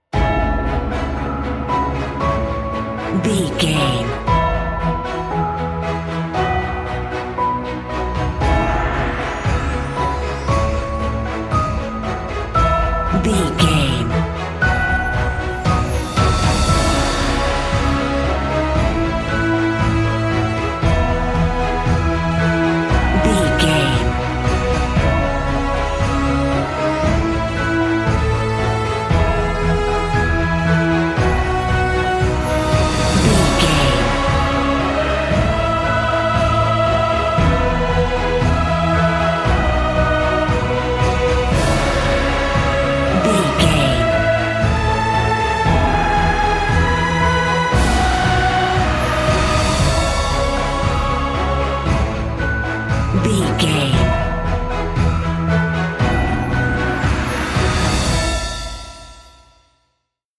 Epic / Action
Uplifting
Ionian/Major
energetic
brass
cello
choir
orchestra
percussion
strings
synthesizer
violin